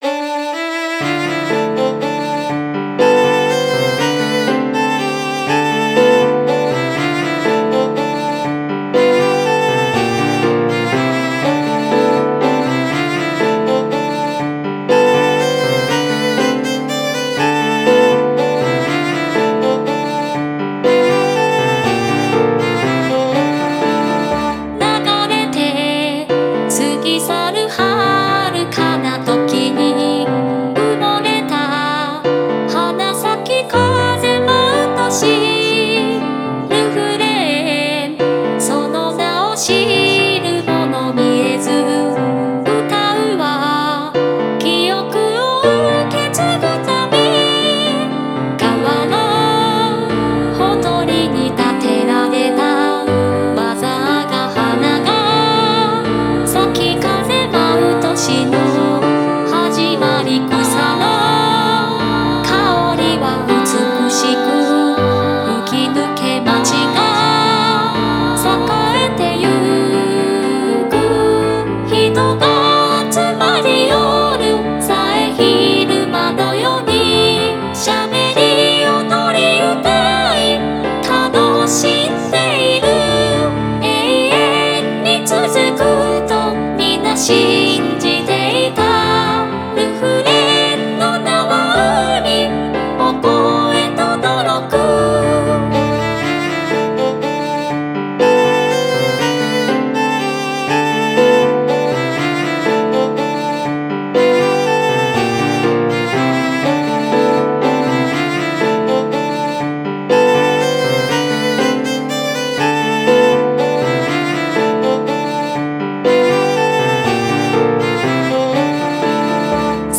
イメージ：古代都市　ジャンル：ケルト音楽、異世界
コメント：ケルト・異世界系ミディアムテンポの曲です。
PIANO伴奏のみでしっとりとお聴きください。